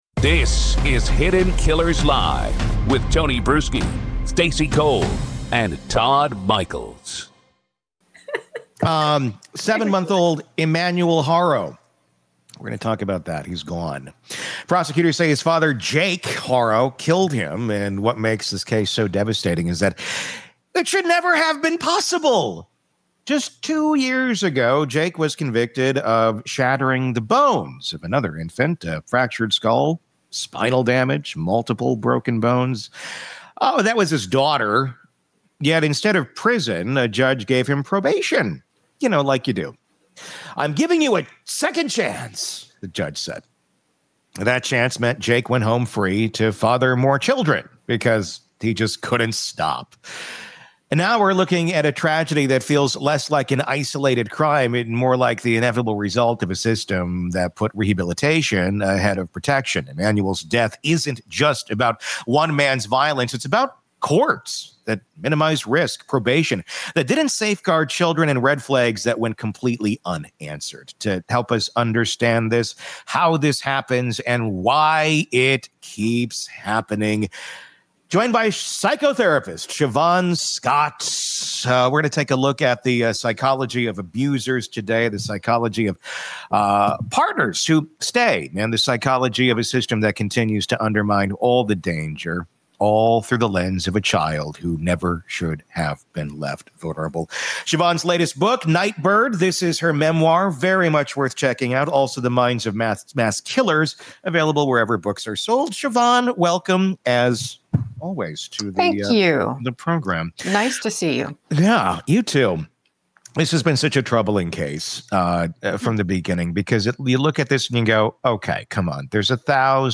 They trace the history of child protection laws in the U.S., from the shocking fact that animals were protected under law before children, to the modern-day failures that leave thousands of kids at risk. This conversation exposes the cracks in a system that too often waits until children are dead or permanently maimed before acting.